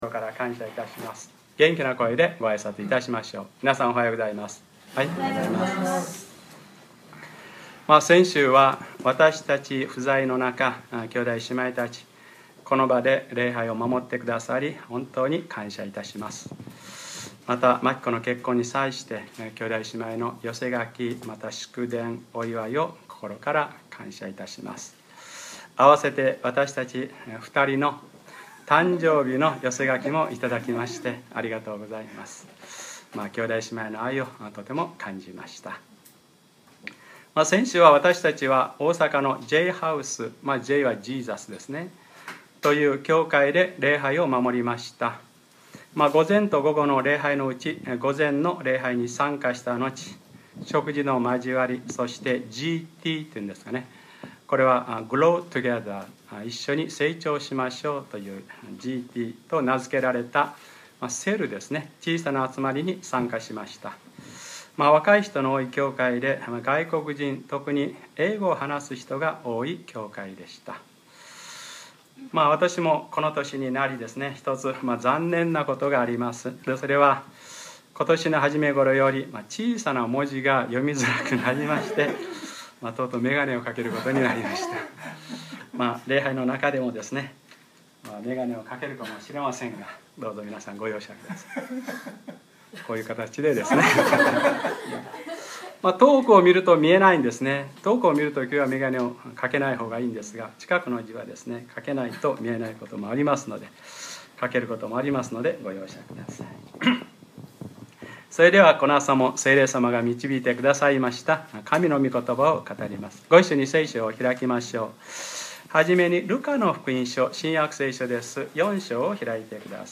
2012年9月16日(日）礼拝説教 『彼は私たちの病を負い』